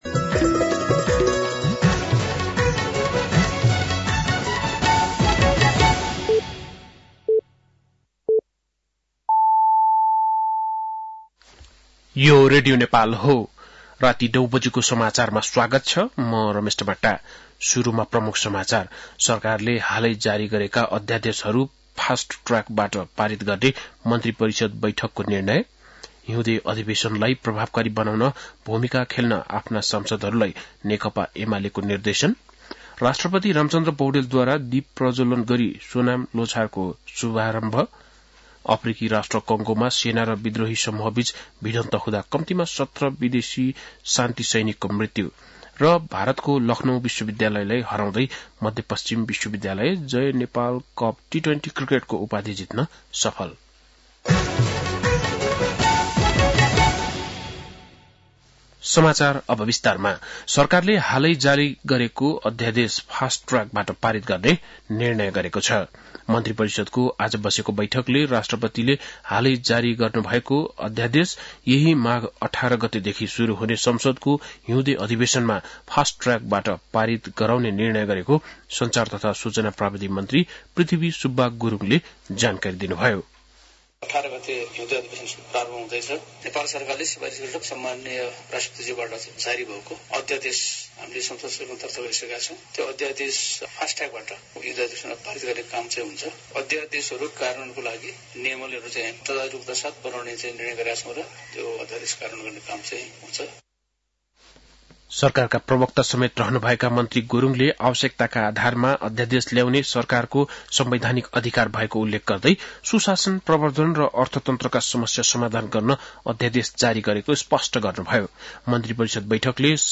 बेलुकी ९ बजेको नेपाली समाचार : १६ माघ , २०८१